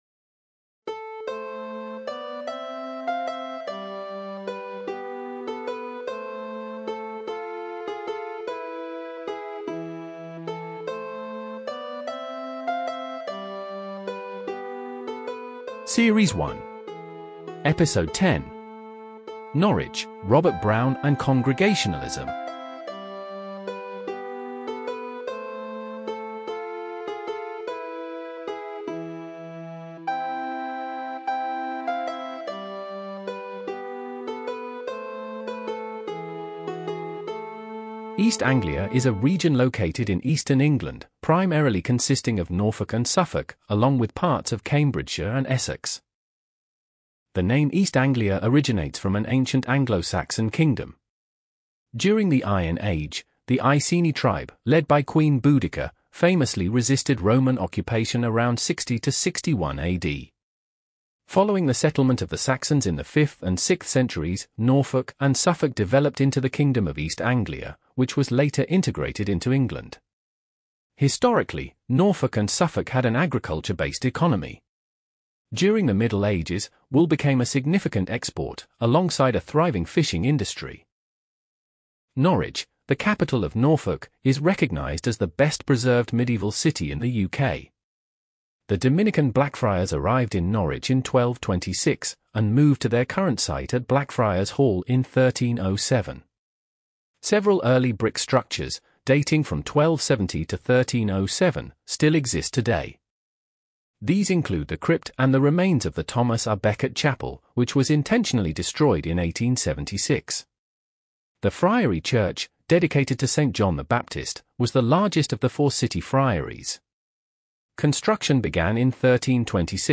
The first piece of music is Greensleeves, a traditional English folk song.
The second music played at the end is T he Old Hundred, a hymn melody from the second edition of the Genevan Psalter.